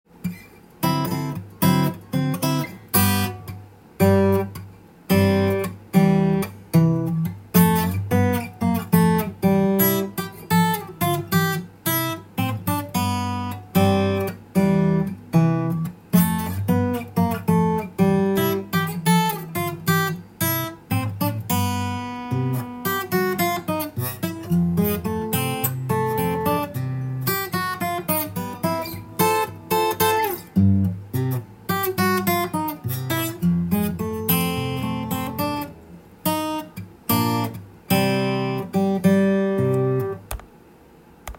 ソロギターで弾けるtab譜
譜面を見ながらアコギで弾いてみました
この曲はカリプソというラテンのリズムで作られているので
アコースティックギターで一人でメロディーとコードを弾く